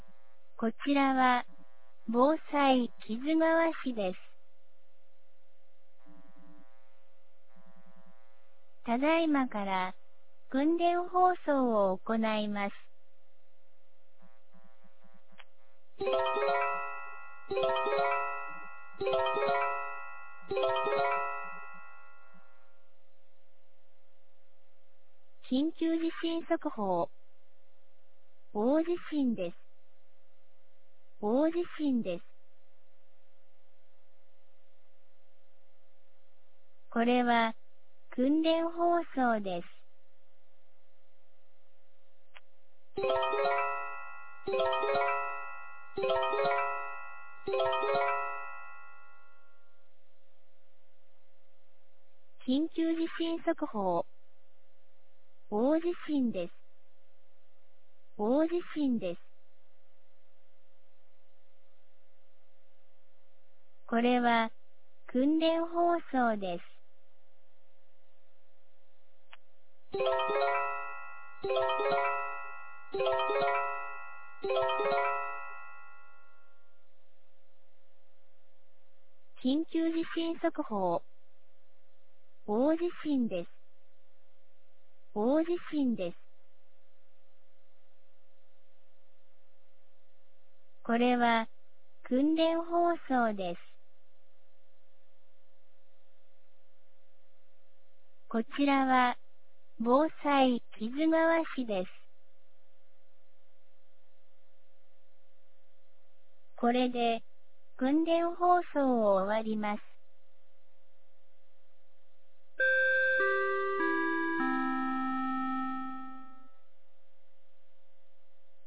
2020年06月17日 10時02分に、木津川市より市全域へ放送がありました。
放送音声